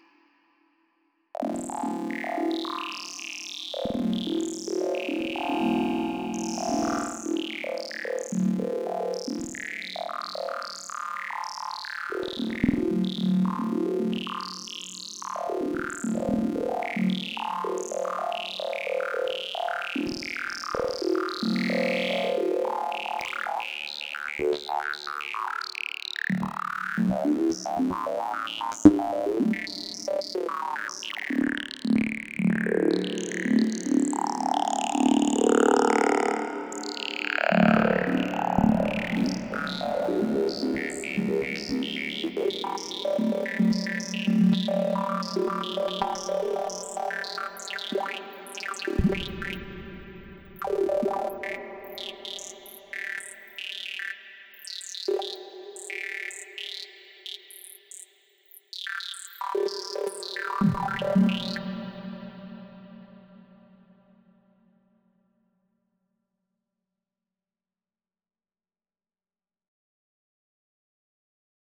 Talking Saw Squelch Lead - Audionerdz Academy
Talking-Saw-Squelch-Lead.wav